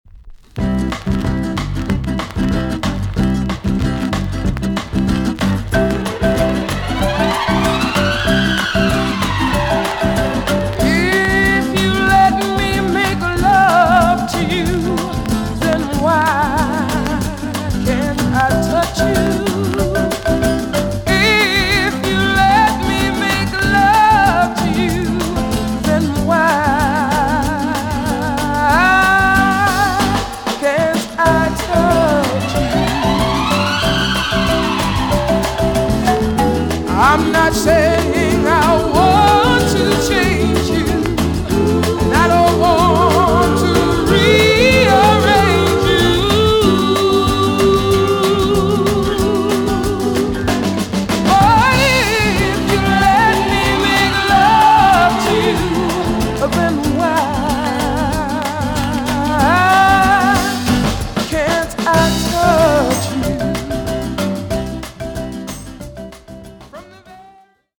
EX-~VG+ 少し軽いチリノイズがありますが良好です。
WICKED NORTHERN SOUL TUNE!!